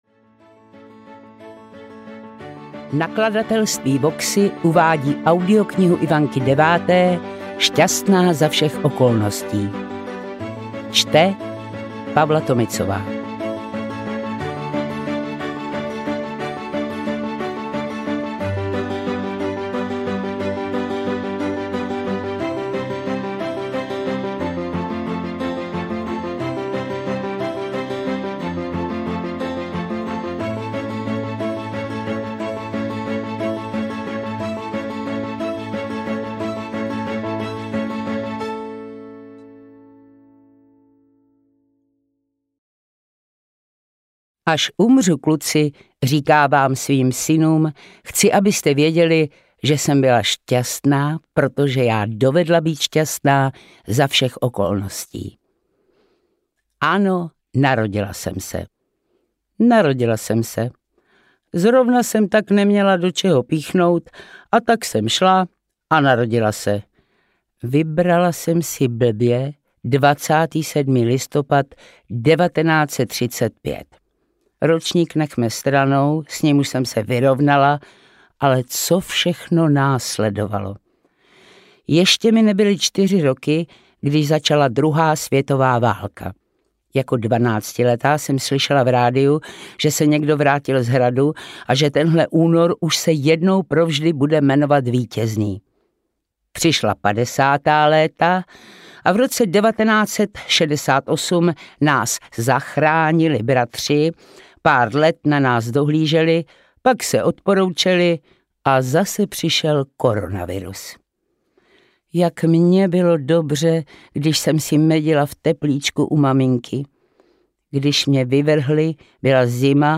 Šťastná za všech okolností audiokniha
Ukázka z knihy
• InterpretPavla Tomicová